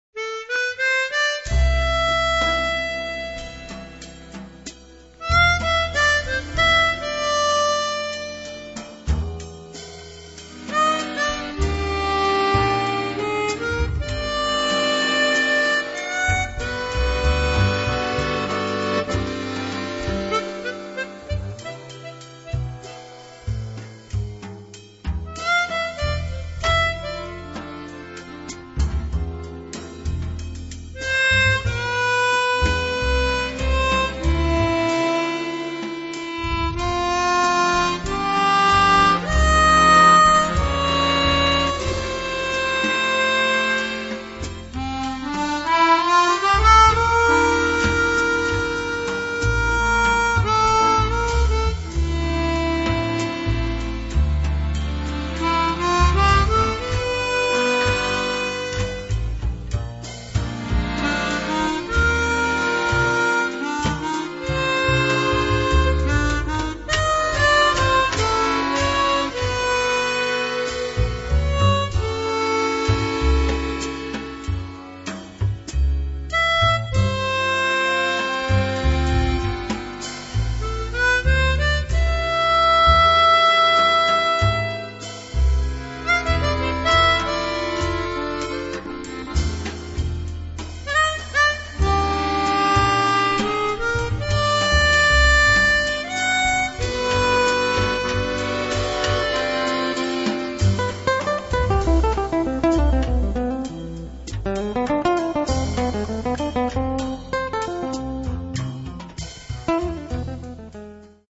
fisarmonica
contrabbasso
batteria